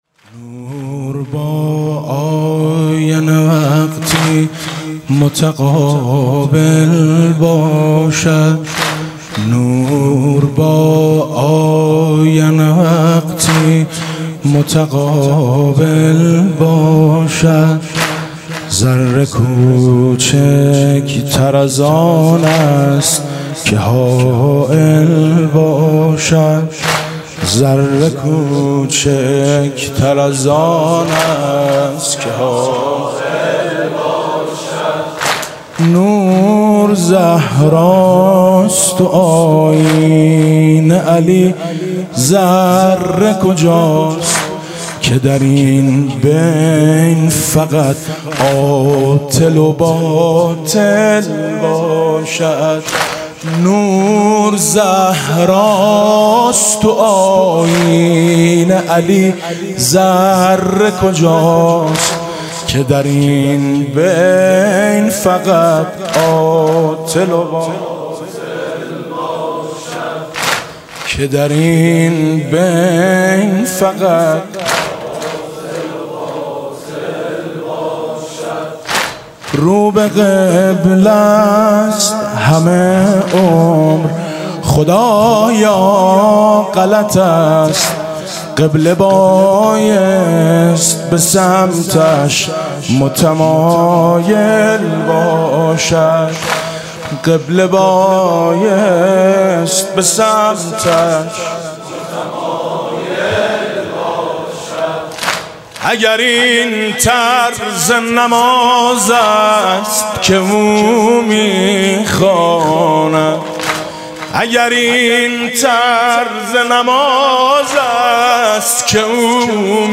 music-icon واحد: نور ،زهراست و آیینه ،علی حاج میثم مطیعی